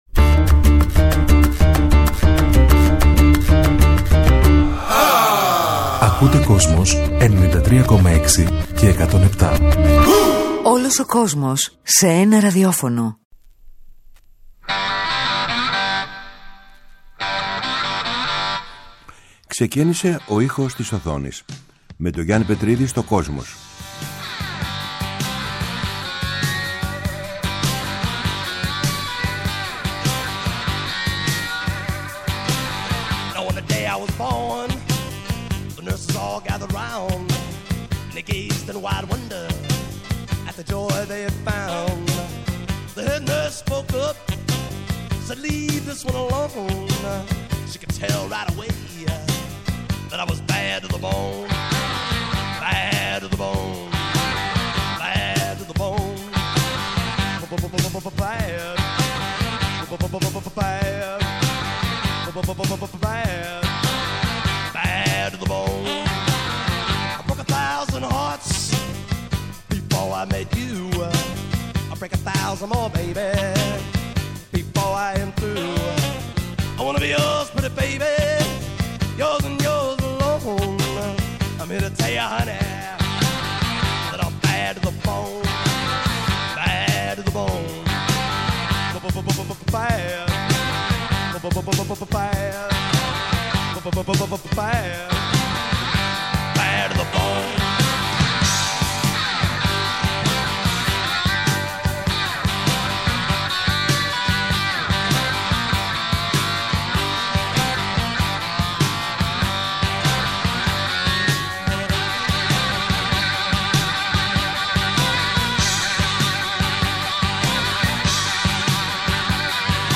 Στις εκπομπές αυτές θα ακουστούν μουσικές που έχουν γράψει μεγάλοι συνθέτες για τον κινηματογράφο, αλλά και τραγούδια που γράφτηκαν ειδικά για ταινίες.